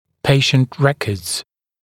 [‘peɪʃnt ‘rekɔːdz][‘пэйшнт ‘рэко:дз]история болезни пациента; документальные данные о пациенте